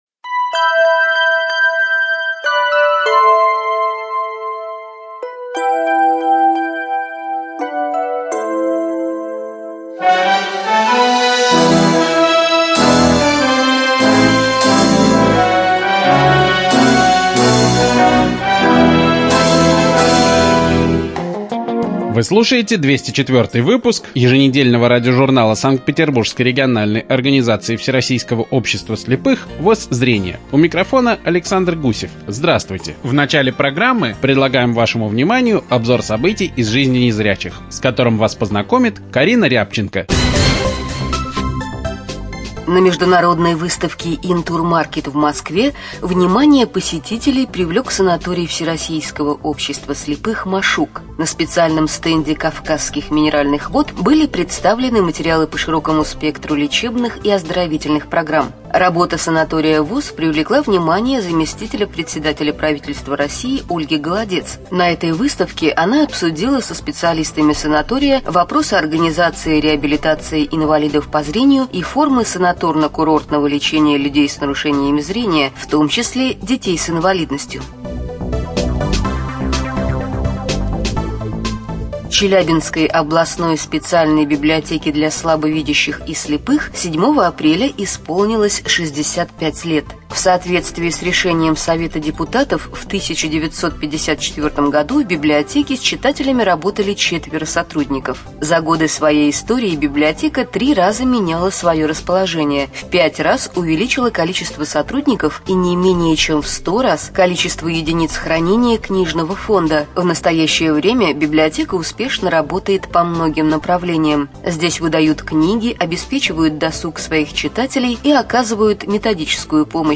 ЖанрРеабилитация, Радиопрограммы